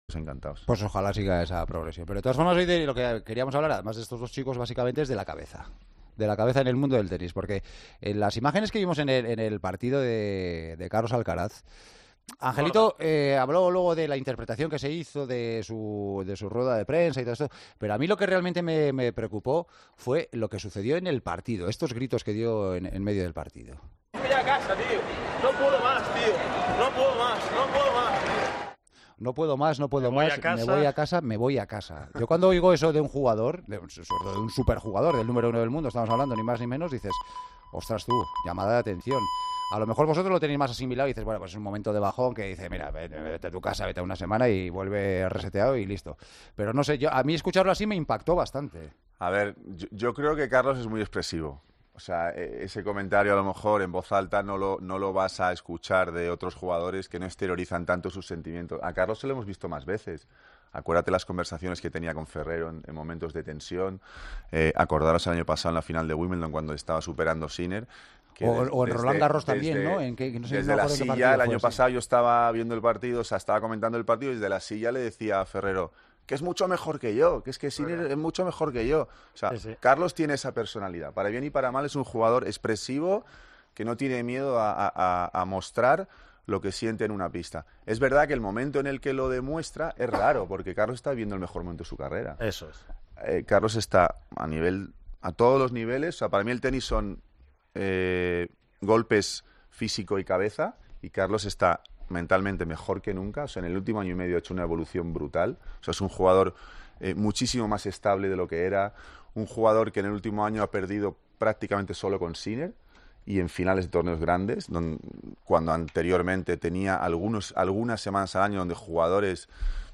El director del Mutua Madrid Open analiza en El Partidazo de COPE el arrebato del murciano en Miami y explica por qué, pese a lo chocante, no es un síntoma preocupante